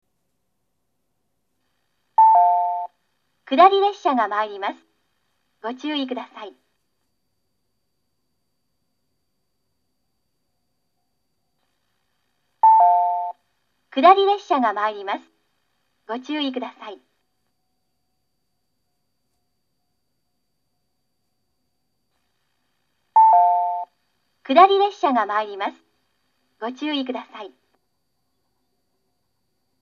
接近放送